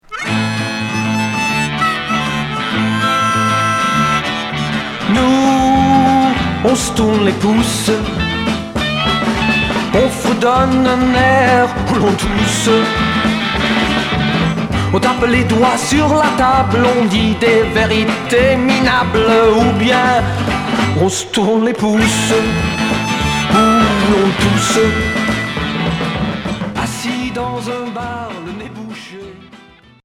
Chanteur 60's